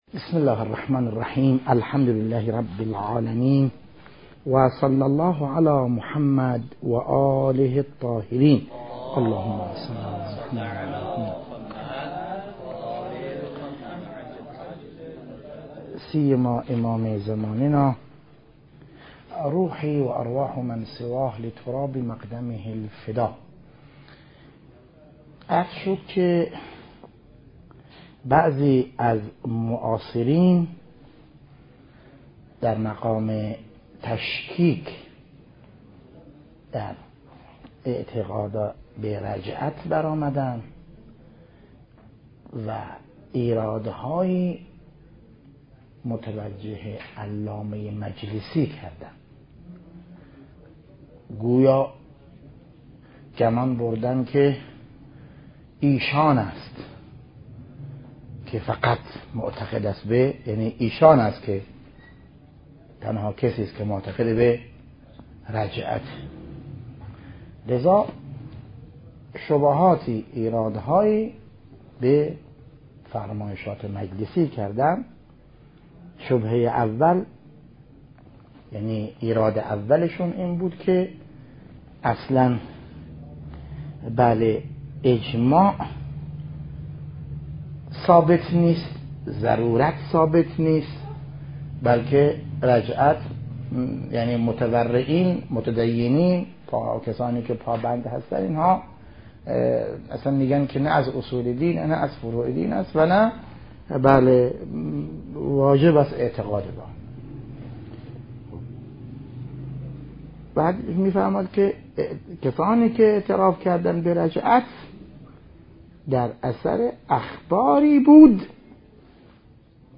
بحث خارج مهدویت - رجعت وجهان پس از ظهور ج 19